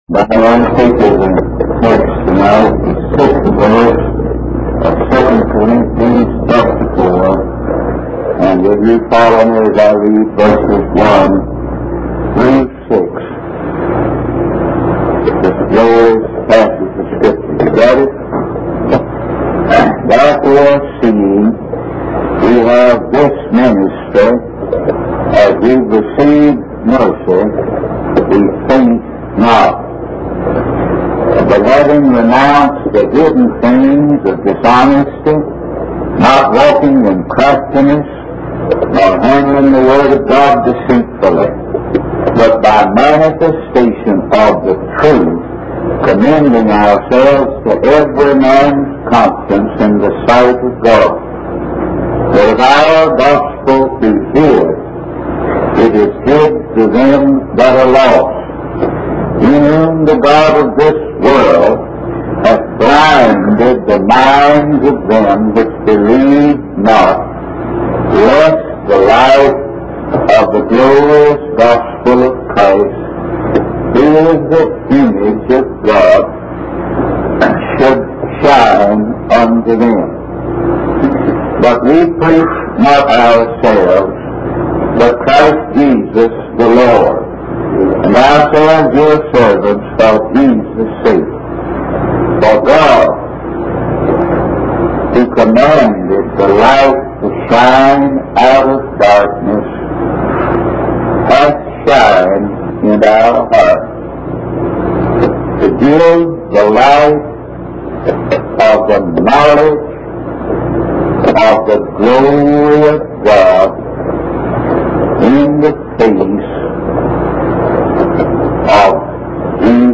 In this sermon, the preacher emphasizes that salvation is only possible through Jesus Christ. He emphasizes the importance of recognizing God's glory in the face of Jesus Christ.